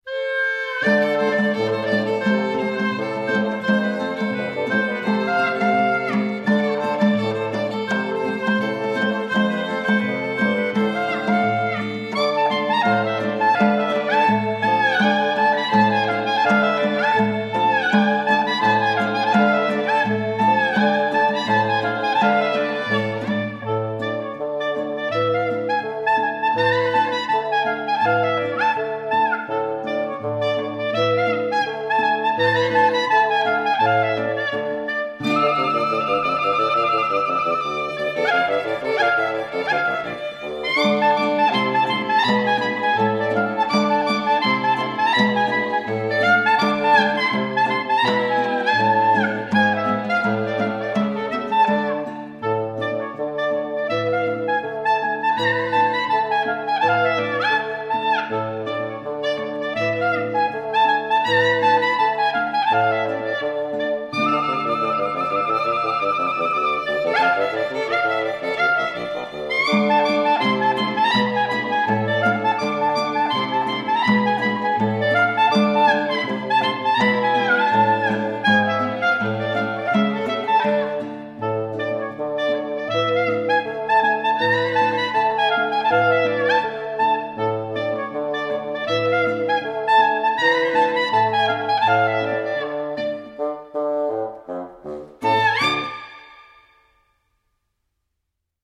yiddish_blues[1].mp3